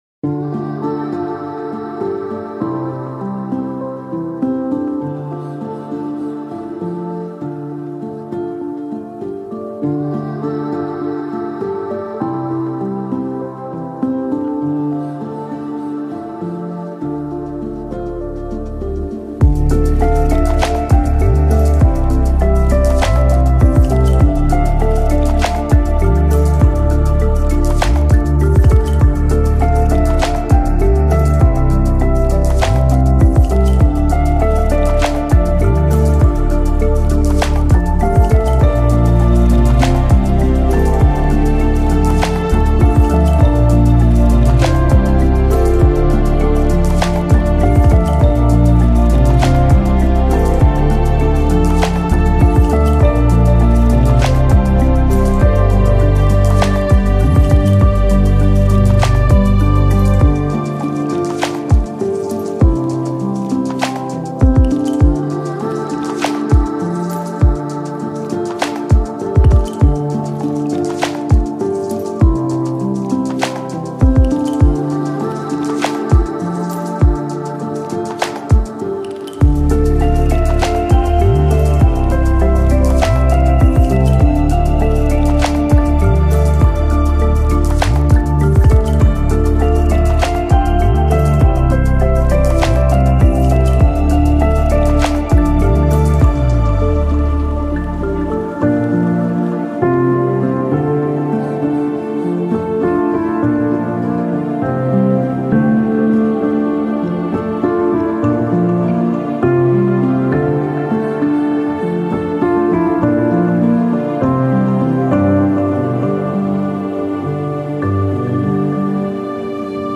отличается мягкими мелодиями и воздушными аранжировками